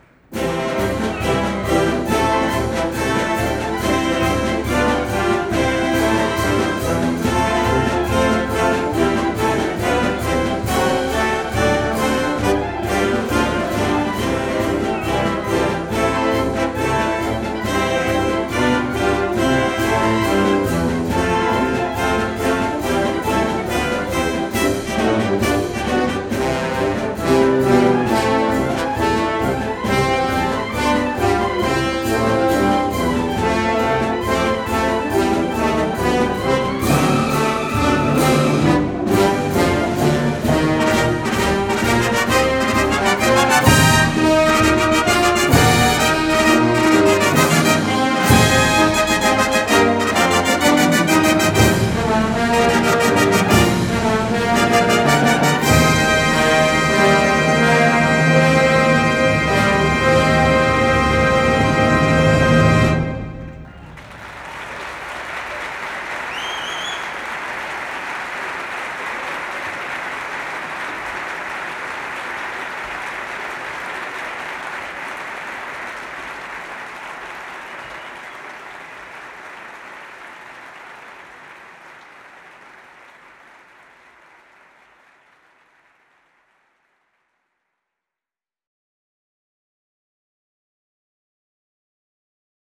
11-Anchors-Away-Live.m4a